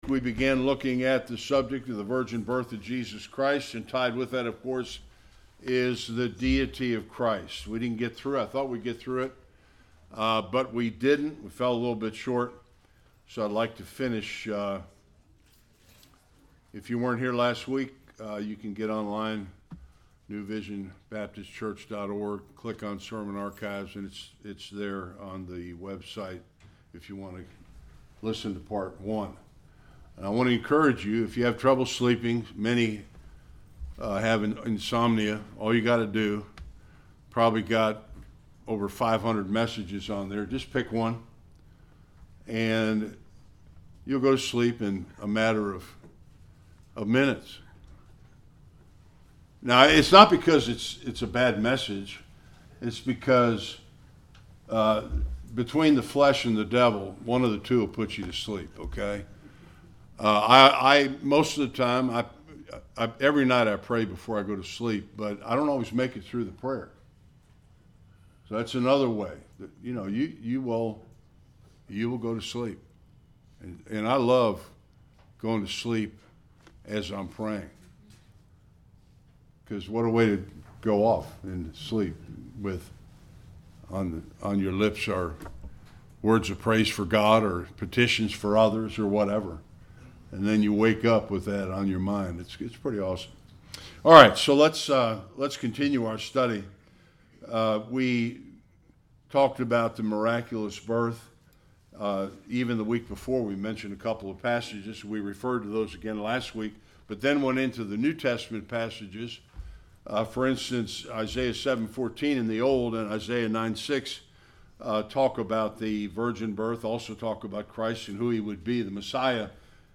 Various Passages Service Type: Sunday Worship The Bible is clear